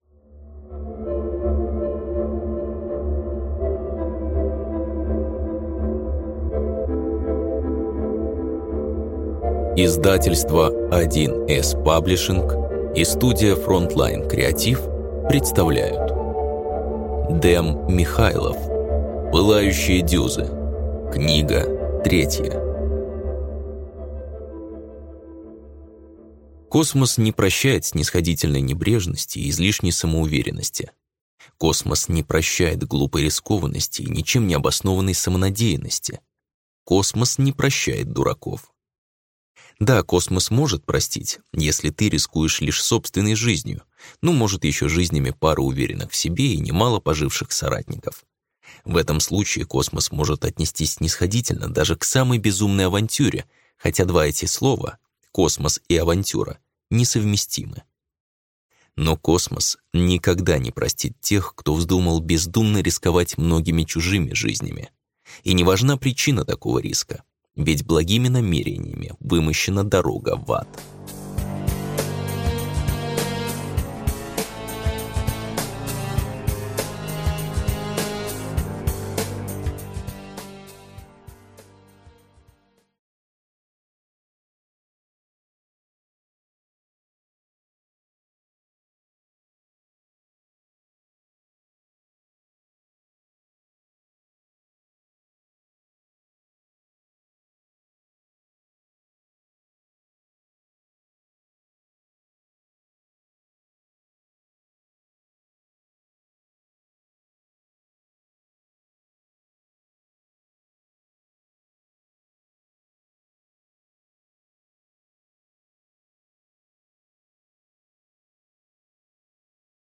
Аудиокнига Пылающие Дюзы – 3 | Библиотека аудиокниг